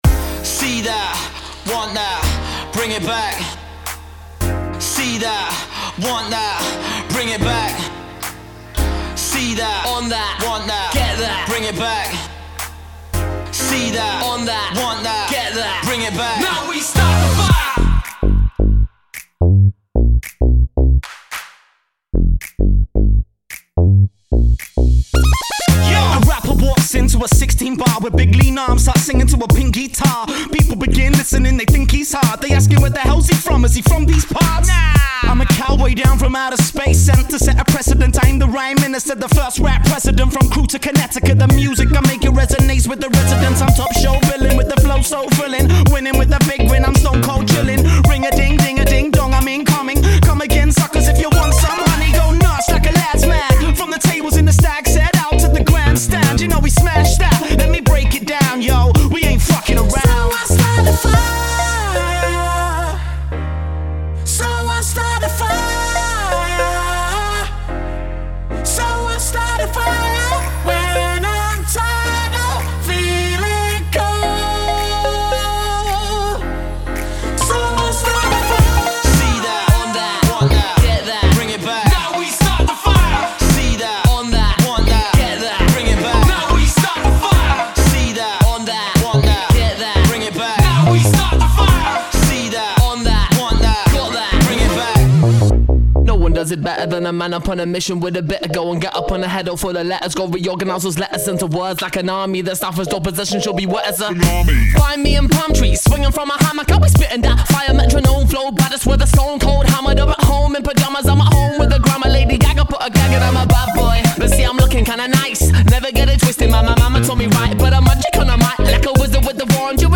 Ghetto Funk
Groovy, Nerdy, FUnky !